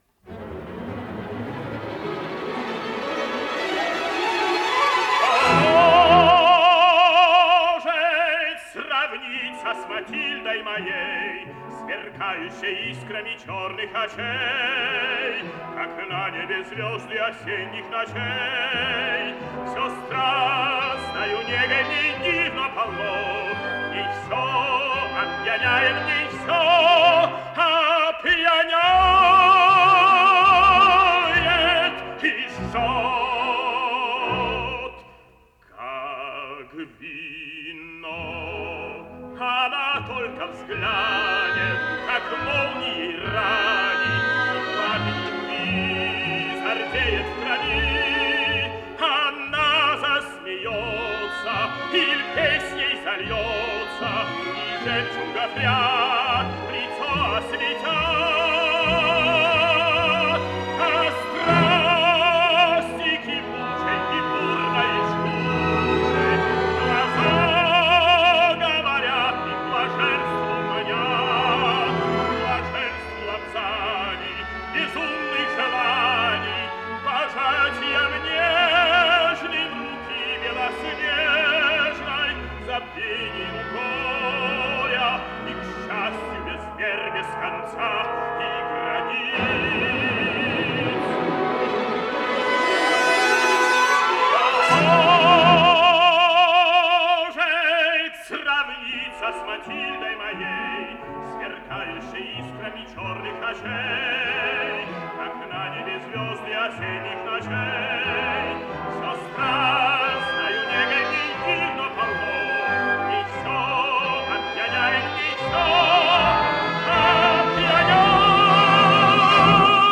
84 года со дня рождения советского певца (баритон), Народного артиста СССР Юрия Антоновича Мазурока